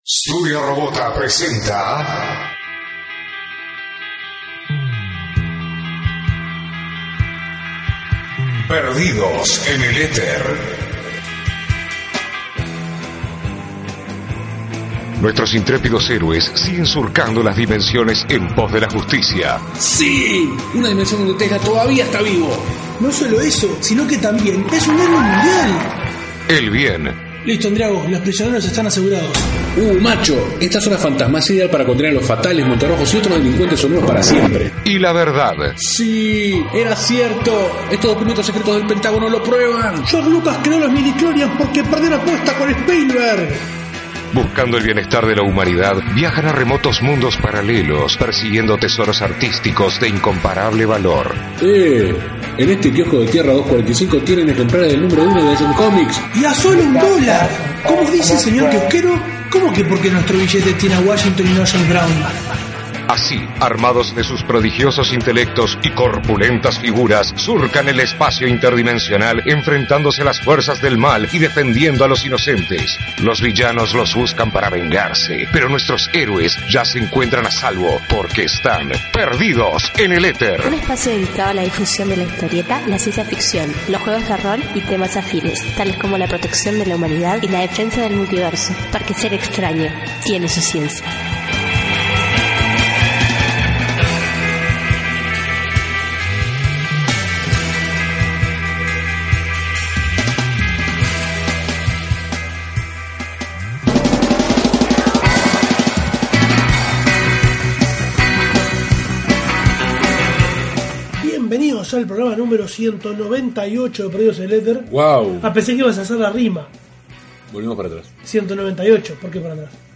Cabe aclarar que la primera charla, que constituye el grueso del programa, fue toda en inglés, ya que la audiencia entendía el idioma y se hacía más práctico.